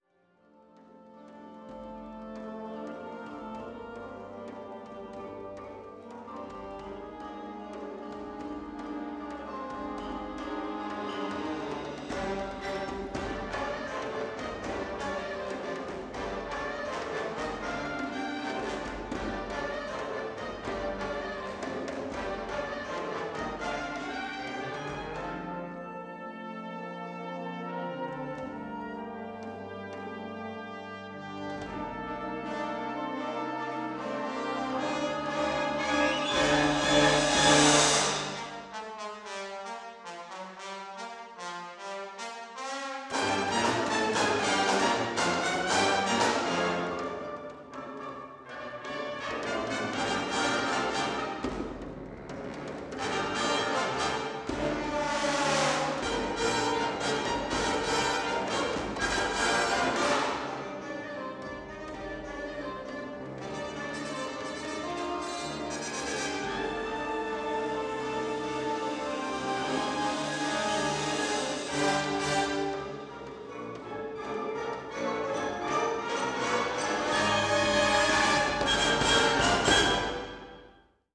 Tetrahedral Ambisonic Microphone
Recorded February 24, 2010, in the Bates Recital Hall at the Butler School of Music of the University of Texas at Austin.